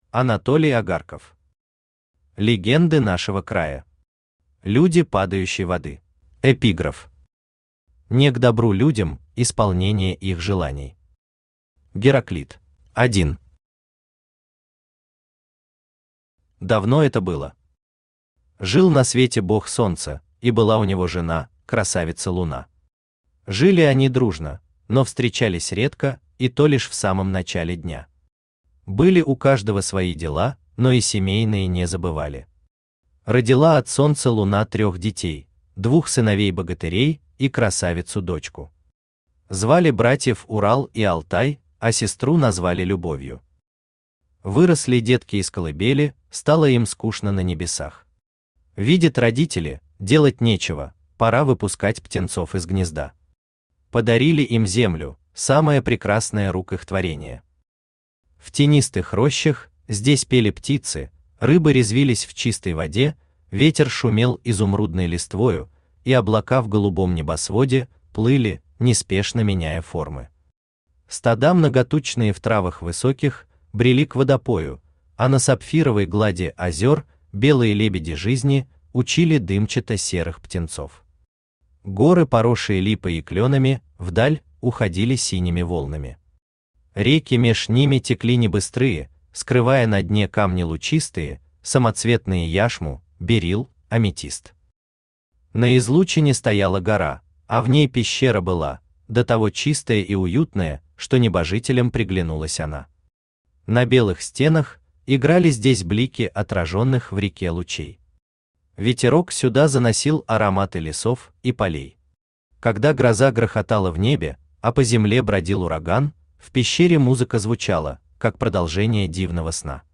Aудиокнига Легенды нашего края. Люди Падающей Воды Автор Анатолий Агарков Читает аудиокнигу Авточтец ЛитРес.